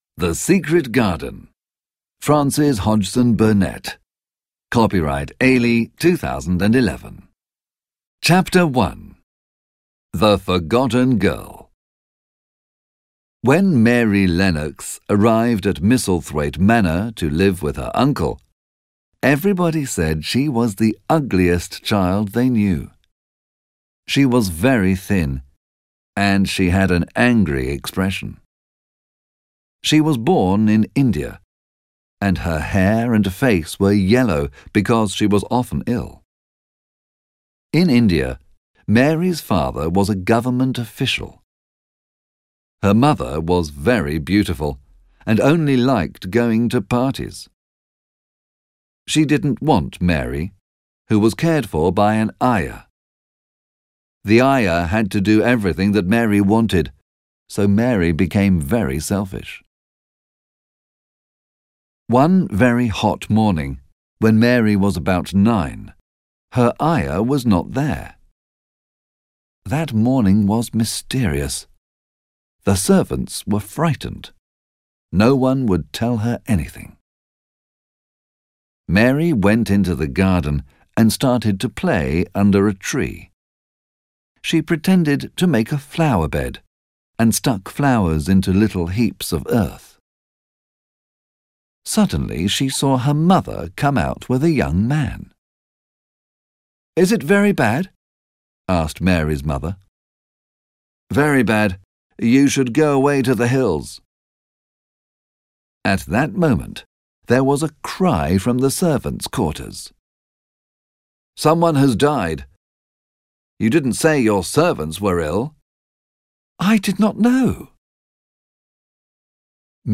Obtížnost poslechu odpovídá jazykové úrovni A2 podle Společného evropského referenčního rámce, tj. pro studenty angličtiny na úrovni mírně pokročilých začátečníků.
AudioKniha ke stažení, 18 x mp3, délka 1 hod. 13 min., velikost 65,6 MB, česky